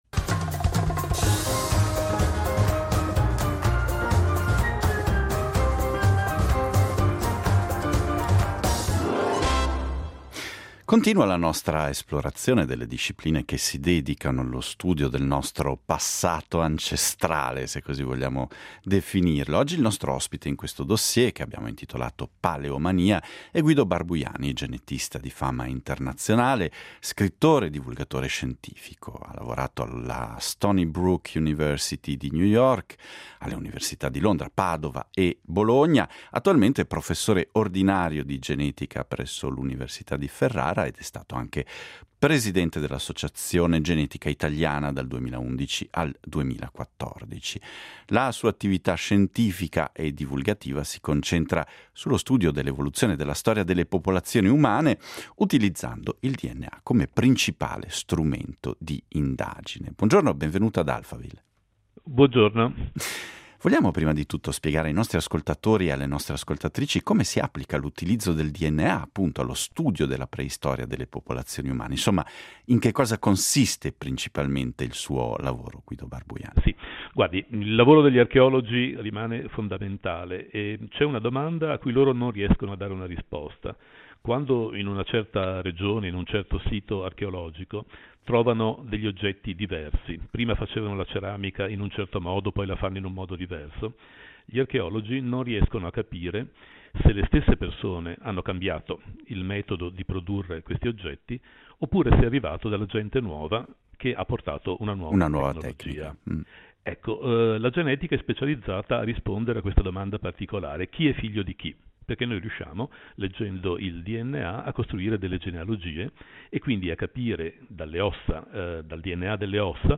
Oggi il nostro ospite del dossier dedicato alla “paleomania” è stato Guido Barbujani , genetista di fama internazionale, scrittore e divulgatore scientifico.